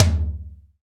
Index of /90_sSampleCDs/Roland - Rhythm Section/TOM_Real Toms 1/TOM_Dry Toms 1
TOM ATTAK 00.wav